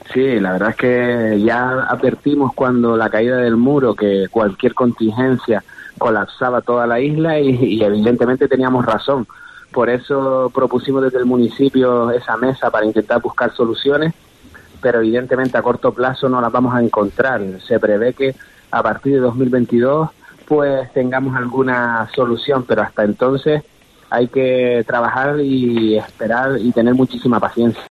El alcalde de Telde, Héctor Suárez, señalaba en nuestros micrófonos, que la caída del muro hace unos meses ya ejemplificó que "cualquier contingencia colapsaba la isla".